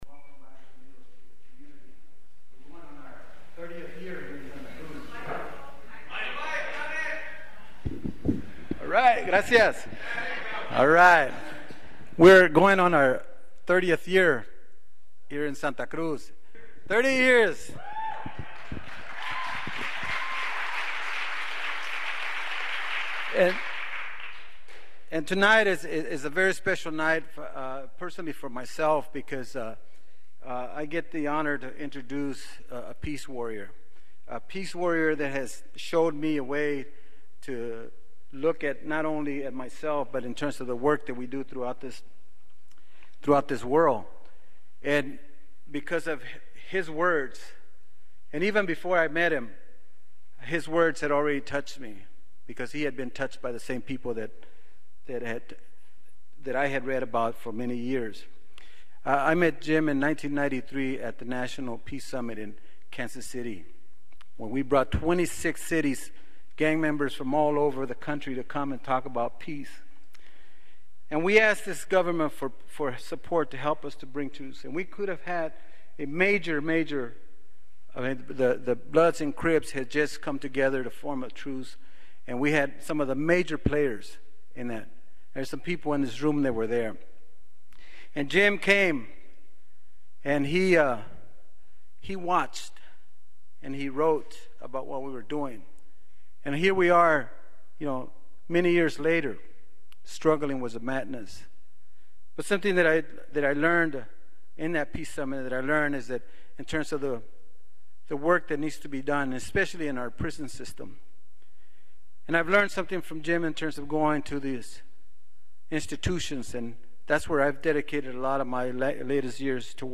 AUDIO: Jim Wallis speaks in Santa Cruz 9/12/06